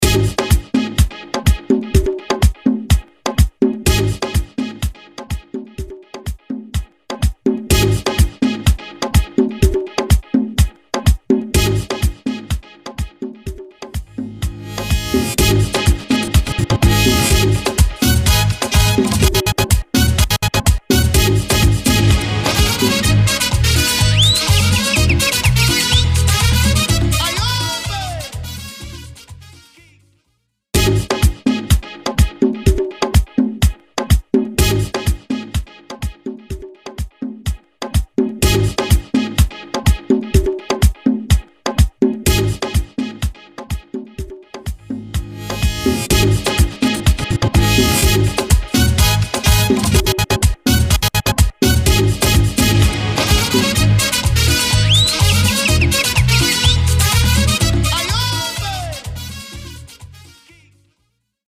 DJ